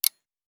clock countdown.ogg